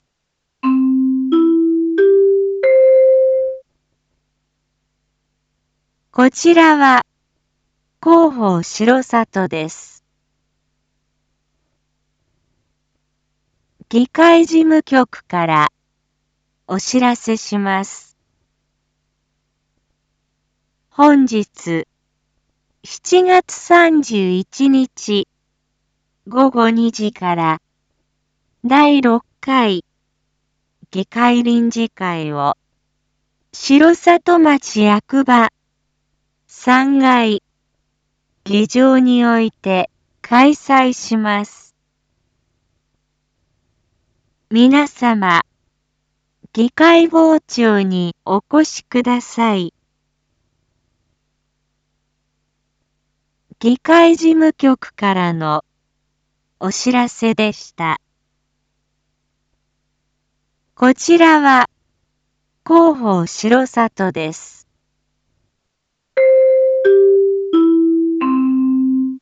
Back Home 一般放送情報 音声放送 再生 一般放送情報 登録日時：2025-07-31 07:01:11 タイトル：②第６回議会臨時会 インフォメーション：こちらは広報しろさとです。